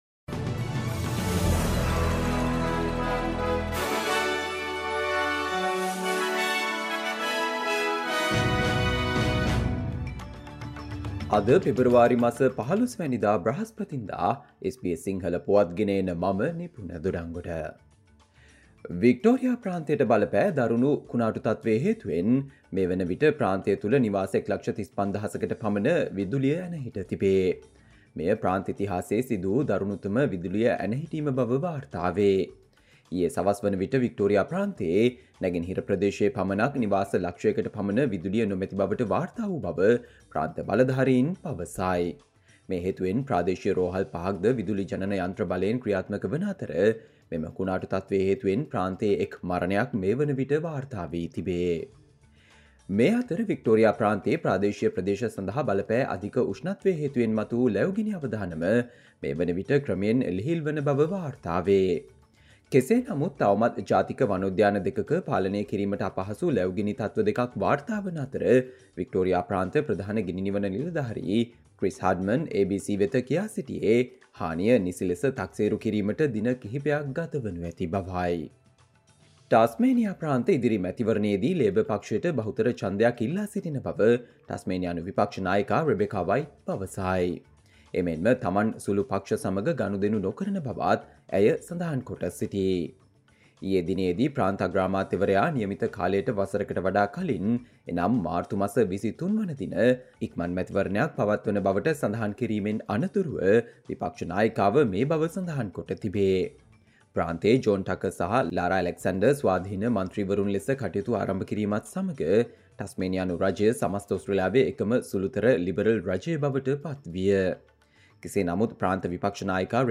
Australia news in Sinhala, foreign and sports news in brief - listen, Thursday 15 February 2024 SBS Sinhala Radio News Flash